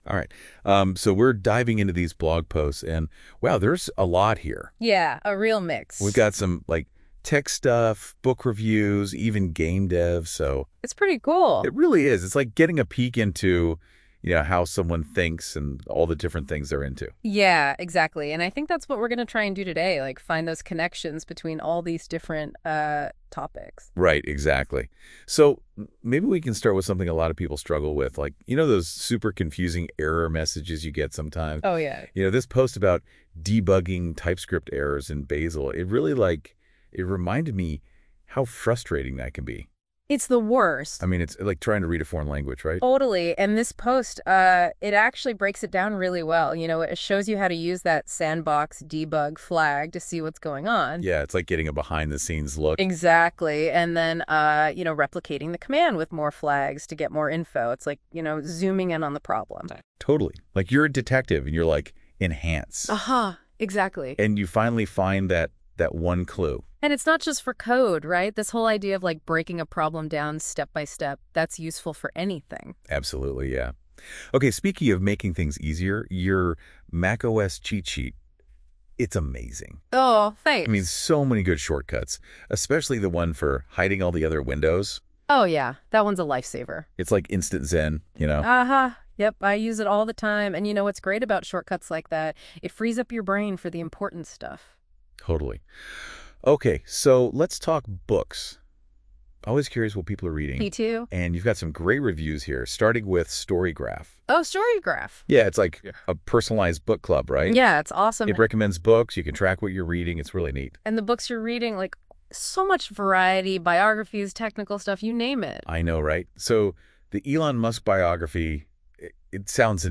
I fed Google's NotebookLM all my 2024 blog posts (in Markdown format), and asked it to do two things: tell me a summary of the content, and generate an audio version of the content overview, which it does in the form of a podcast episode in which two speakers comment.
2024-blog-posts-notebooklm-summary.mp3